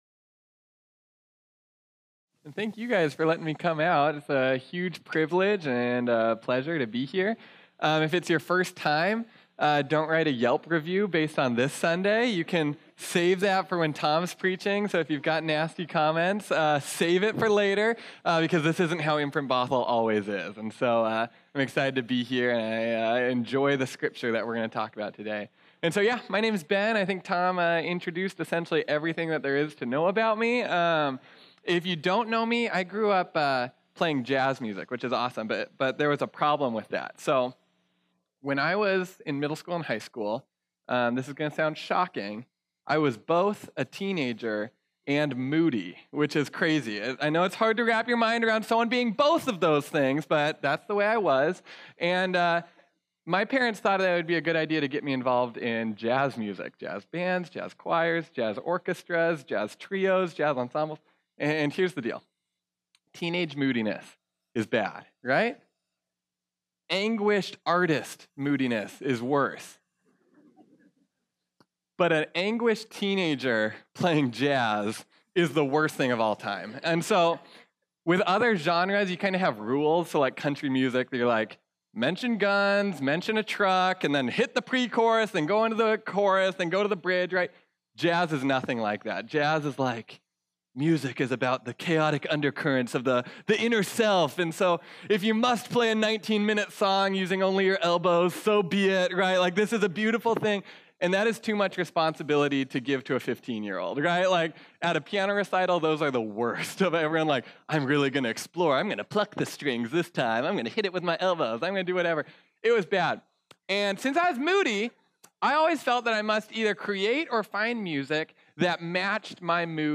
This sermon was originally preached on Sunday, October 28, 2018.